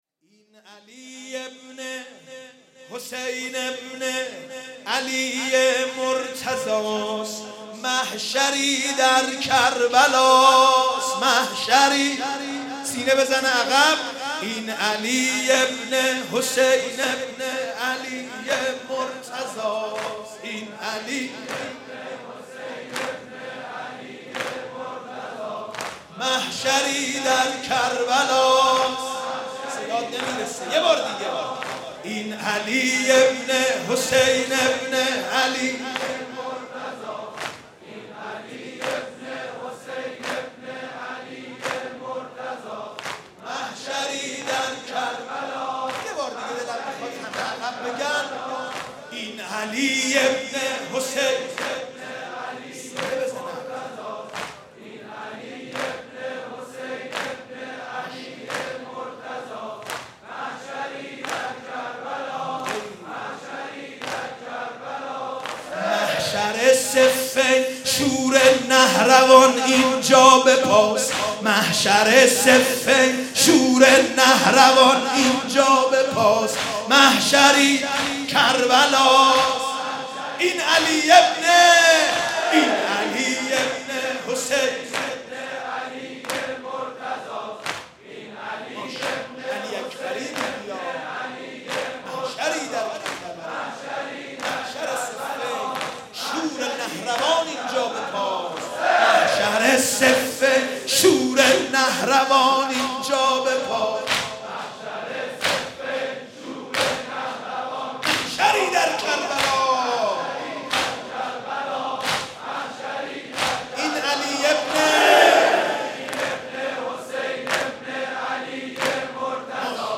(دودمه)